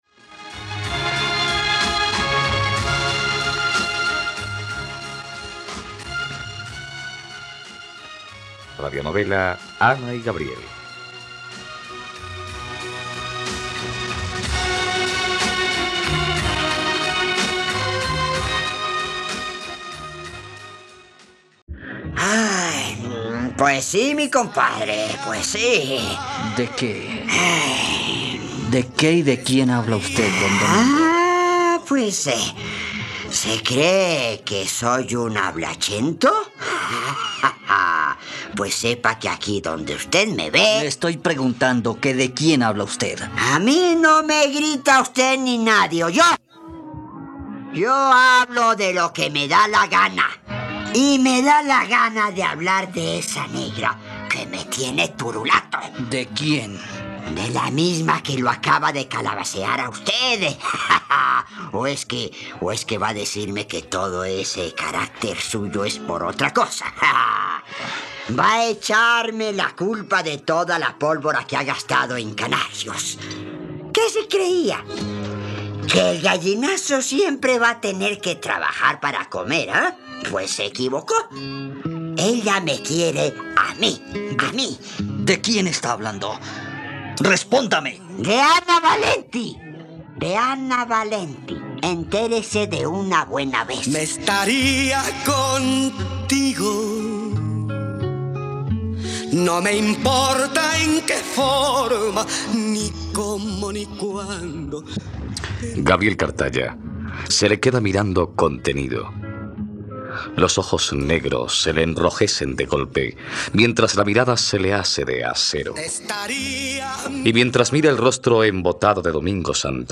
..Radionovela. Escucha ahora el capítulo 36 de la historia de amor de Ana y Gabriel en la plataforma de streaming de los colombianos: RTVCPlay.